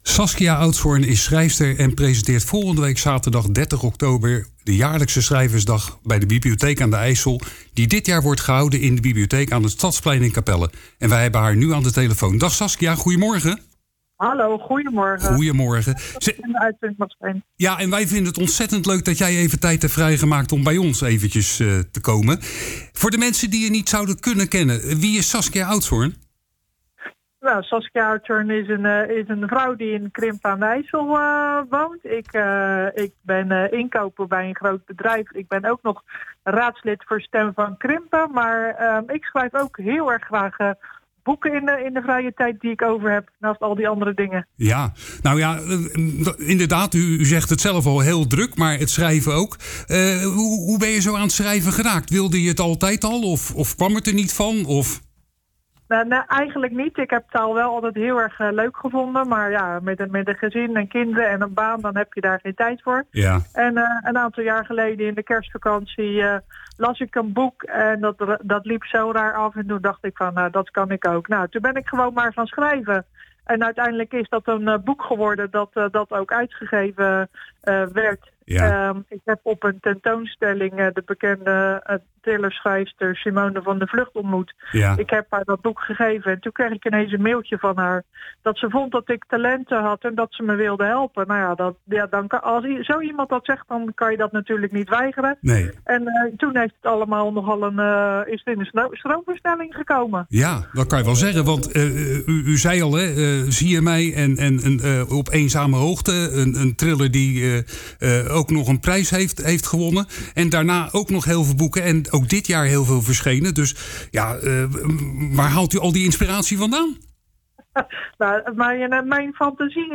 In�deze�podcast�een�gesprek�met�haar, uit het programma Kletskoek.